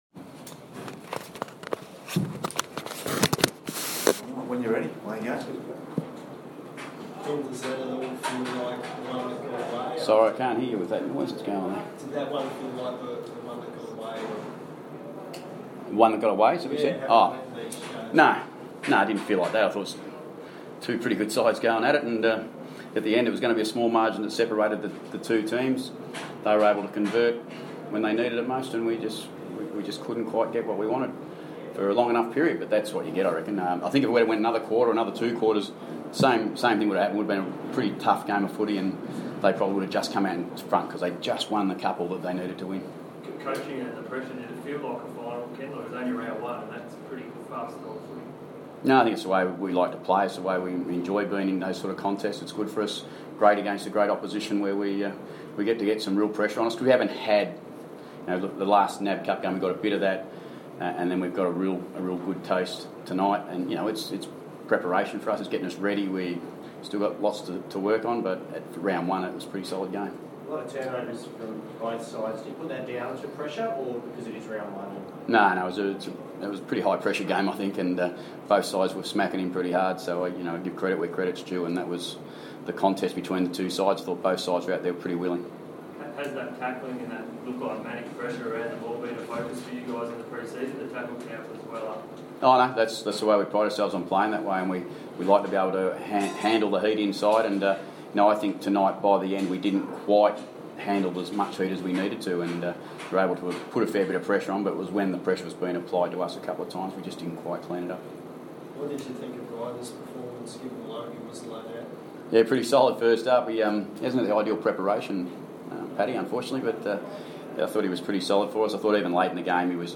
Ken Hinkley post match press conference, April 5, 2015